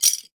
Ui_items_bottlecaps_down_01.ogg